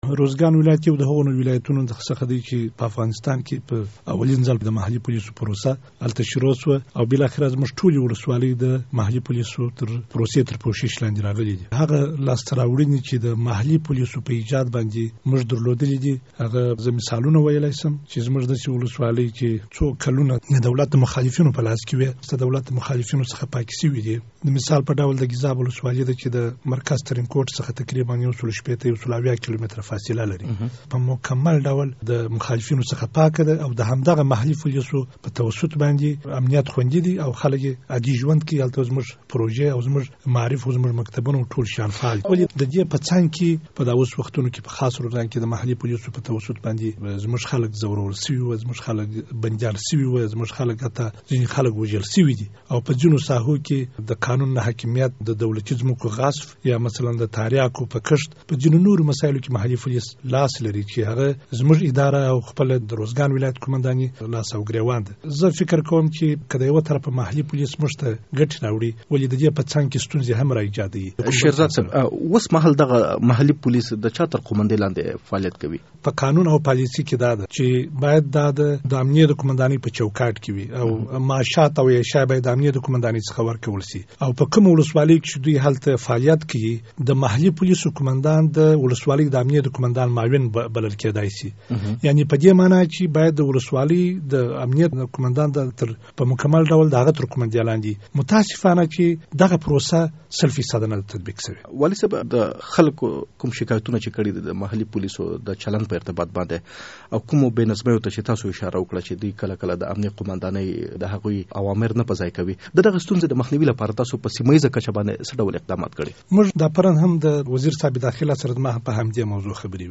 د ارزګان له والي محمد عمر شیرزاد سره مرکه